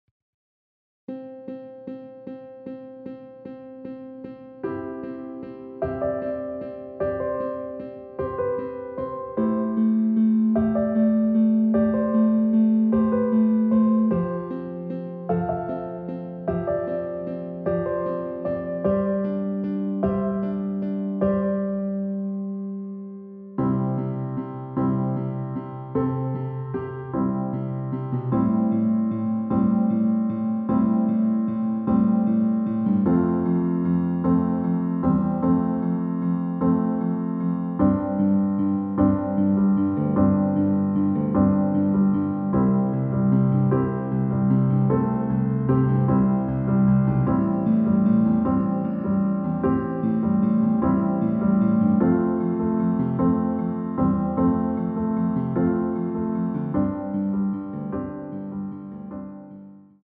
원키 MR입니다.
앞부분30초, 뒷부분30초씩 편집해서 올려 드리고 있습니다.
중간에 음이 끈어지고 다시 나오는 이유는